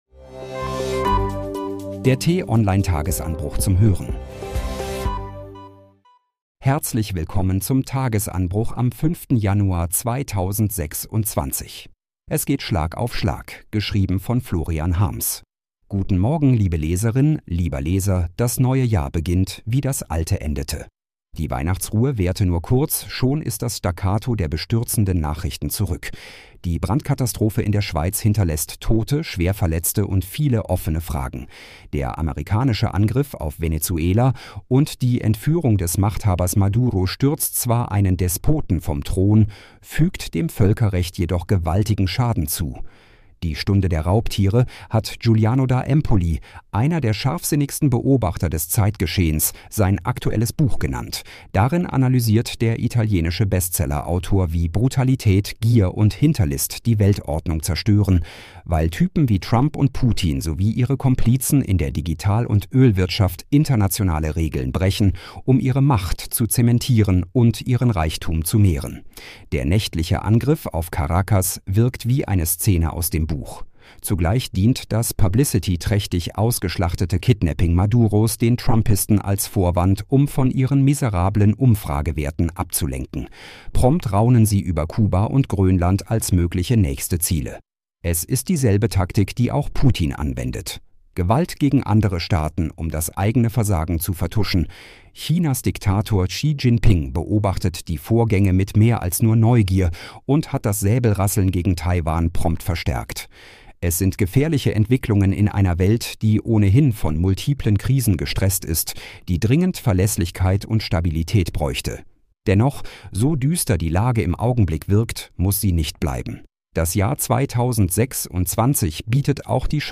zum Start in den Tag vorgelesen von einer freundlichen KI-Stimme –